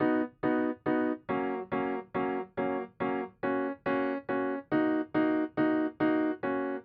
我只是用了一个完整的原声钢琴，所以你可以随意添加你认为必要的东西。
在B调中。
标签： 140 bpm Hip Hop Loops Piano Loops 1.15 MB wav Key : B
声道立体声